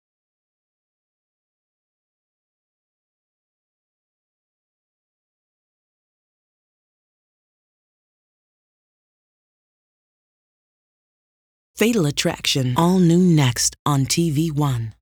FA103_AllNew_Next15_vo.wav